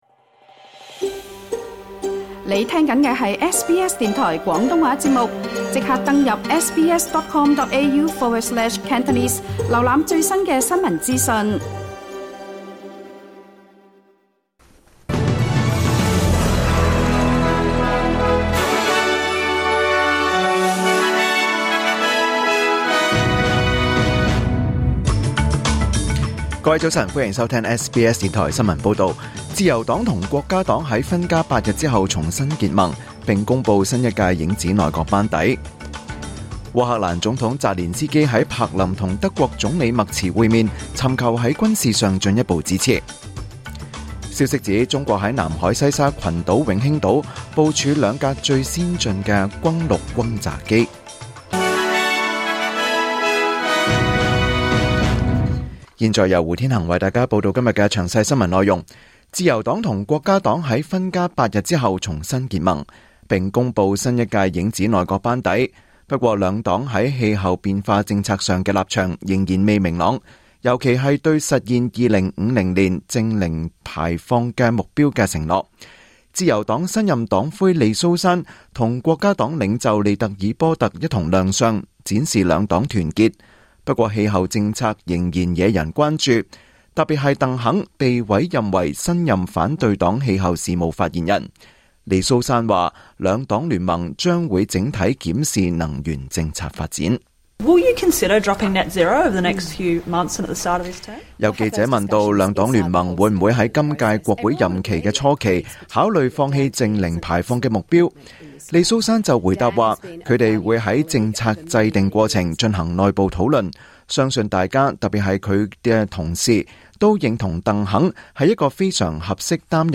2025年5月29日 SBS 廣東話節目九點半新聞報道。